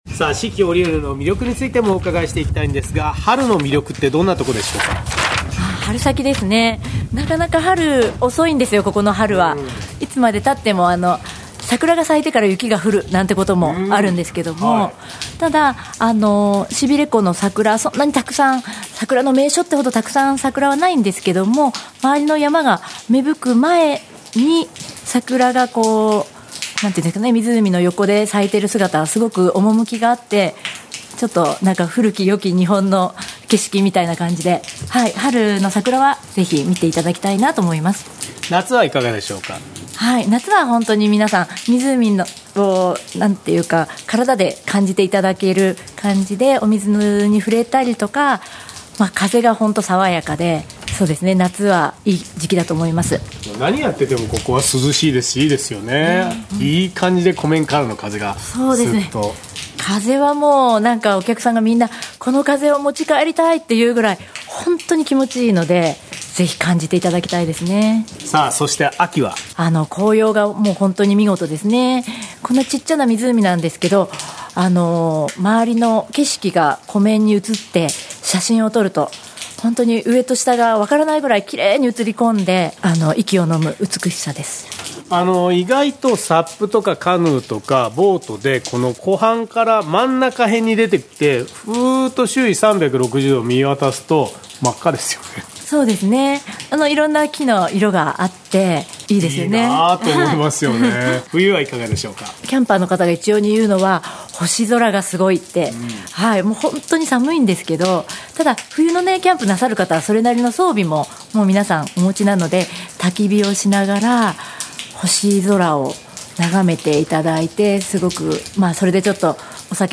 毎週土曜午前11時からの生放送。